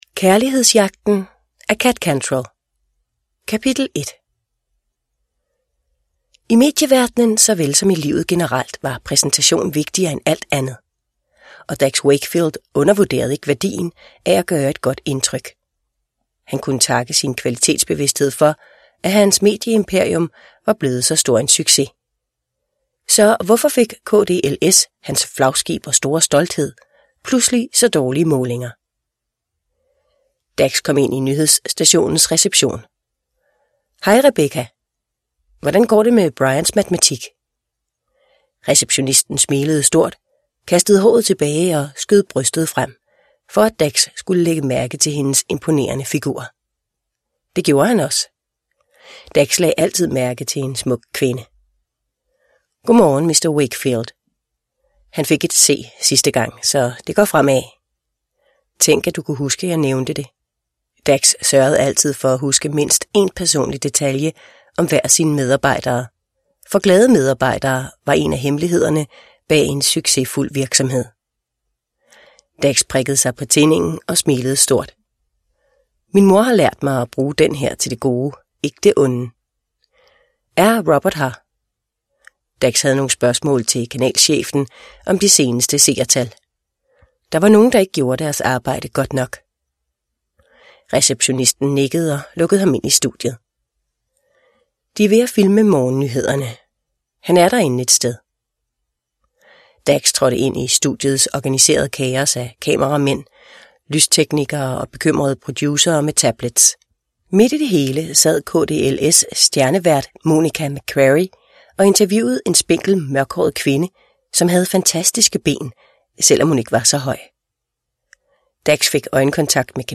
Kærlighedsjagten – Ljudbok – Laddas ner